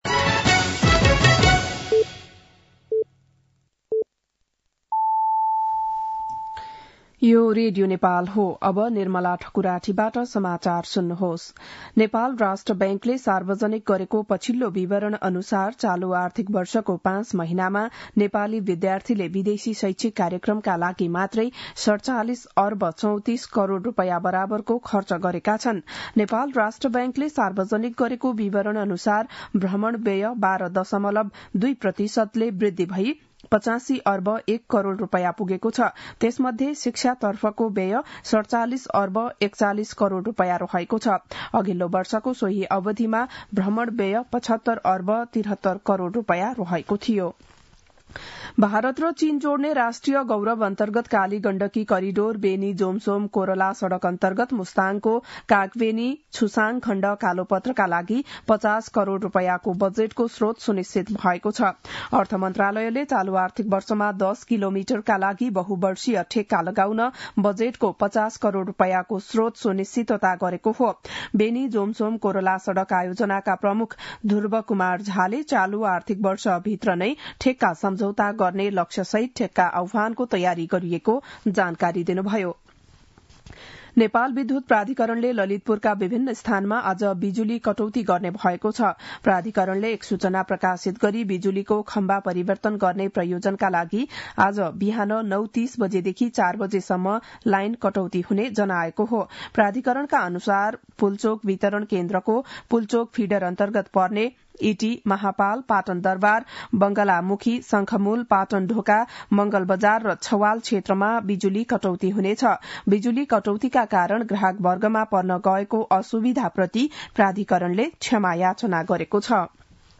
बिहान ११ बजेको नेपाली समाचार : ५ माघ , २०८१